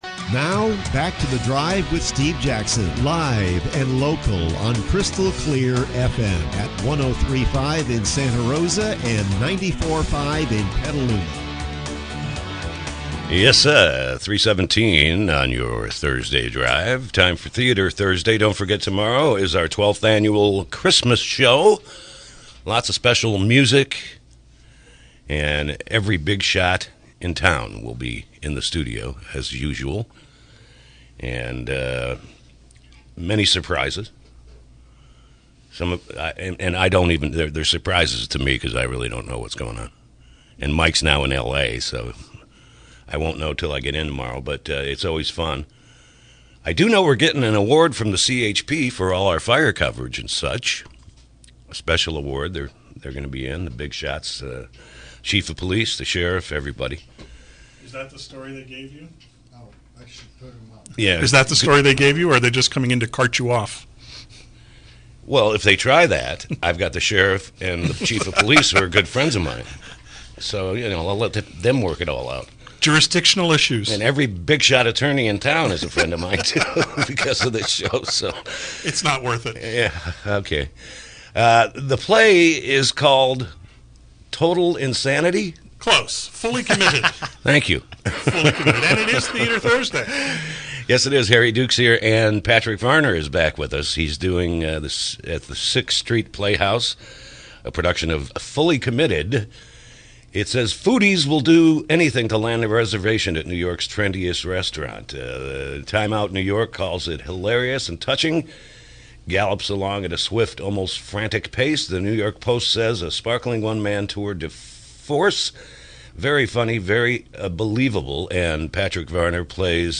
KSRO Interview: “Fully Committed”